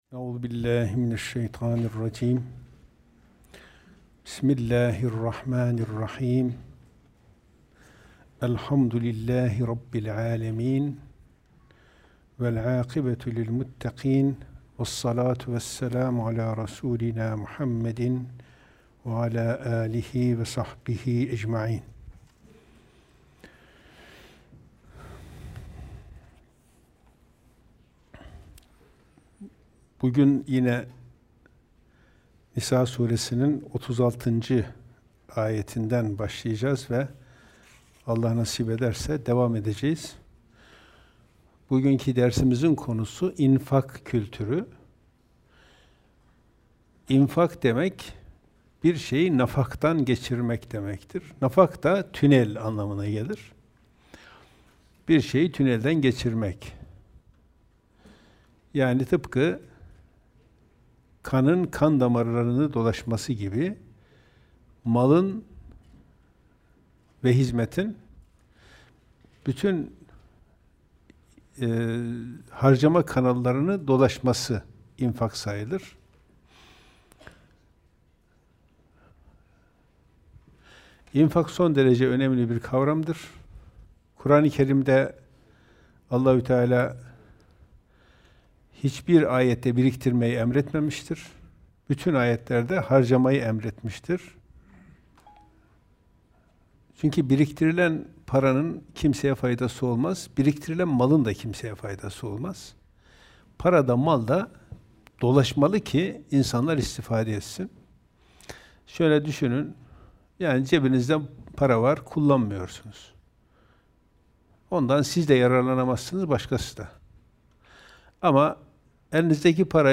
2.332 görüntülenme Kur'an Sohbetleri Etiketleri